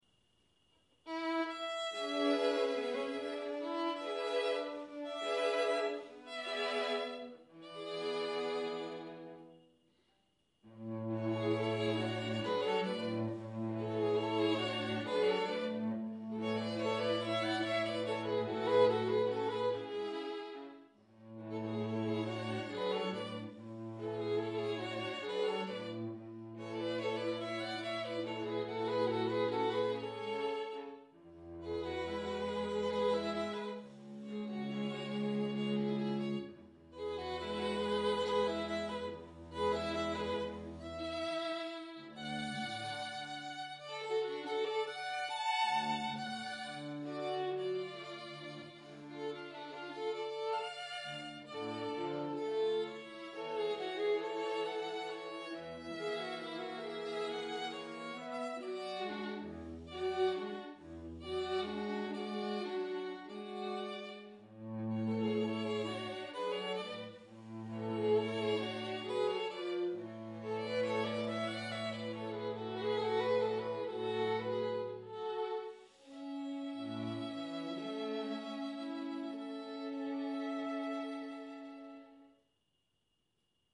Classical Music Samples
These music samples demonstrate the sound of our string quartet; And they give example to the scope of musical mood and style within our performance literature.
Opera --